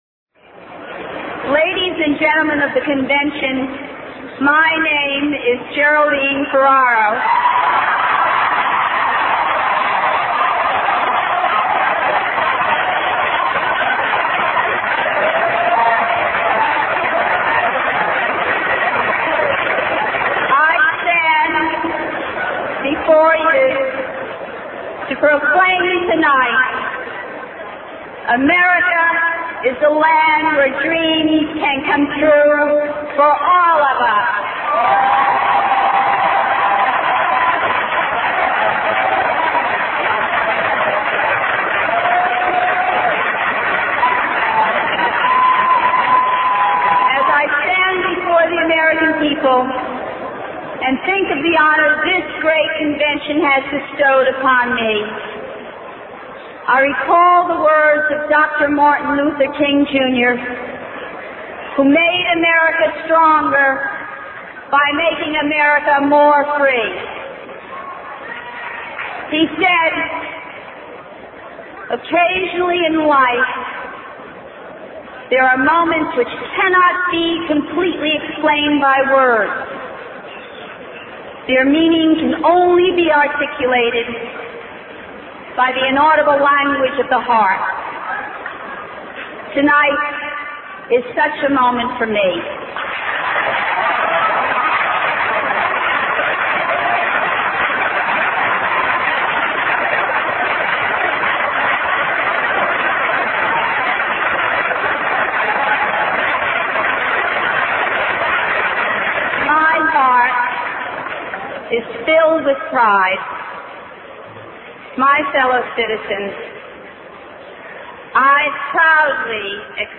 Vice-Presidential Nomination Acceptance Speech